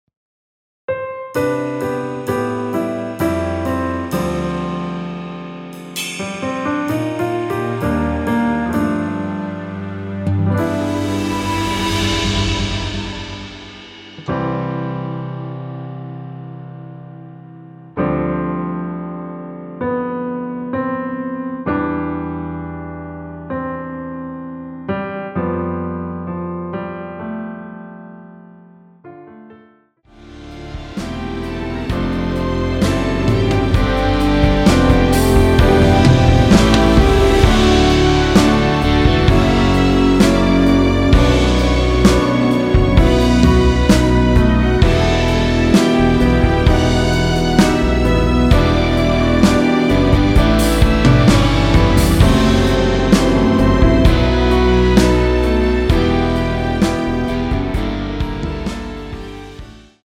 원키에서(-3) 내린 MR 입니다.
앞부분30초, 뒷부분30초씩 편집해서 올려 드리고 있습니다.
중간에 음이 끈어지고 다시 나오는 이유는